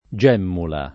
[ J$ mmula ]